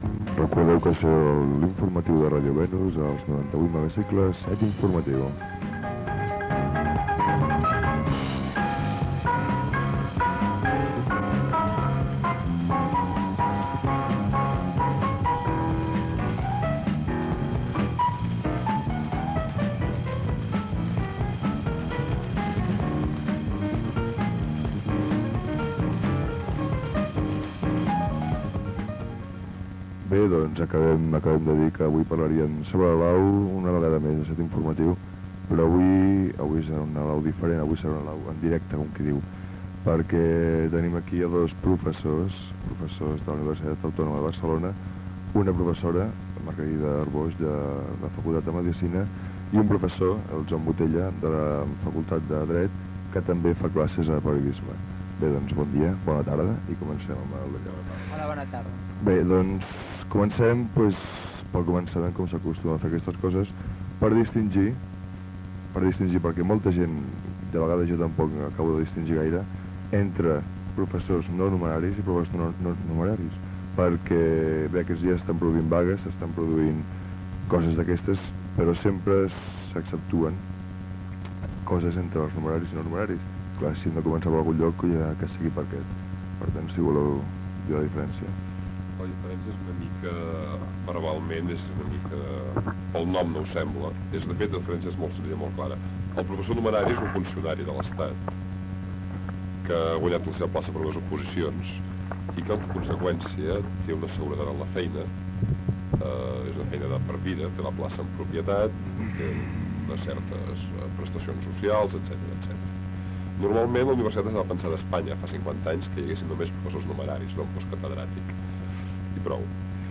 Gènere radiofònic Informatiu